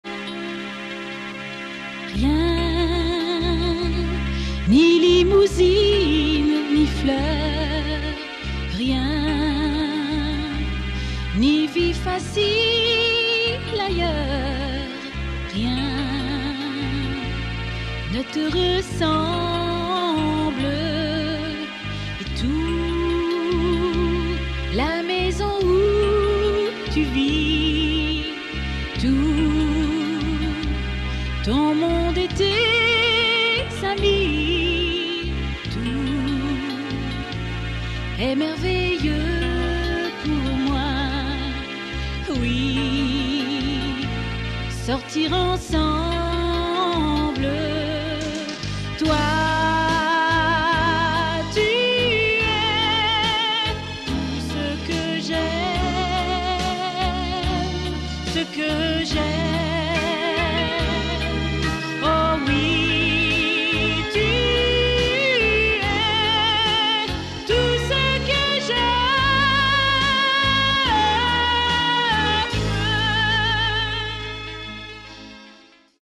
EXTRAIT SLOWS
(finale 1er prix concours chansons françaises)